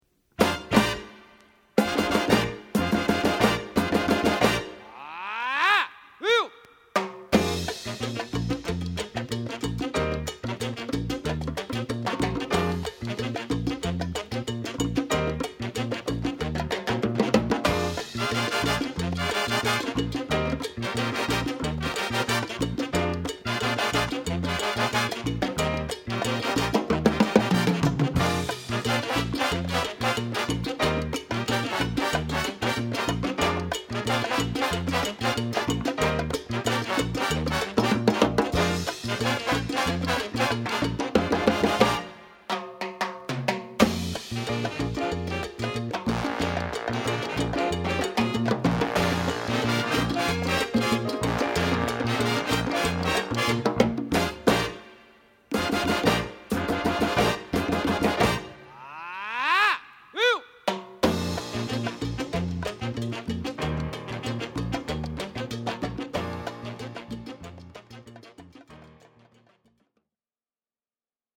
音質・音圧も抜群のダイレクトカッティング・シリーズです。
JAPAN Press AUDIOPHILE 高音質プレス w./Shrink 美品!!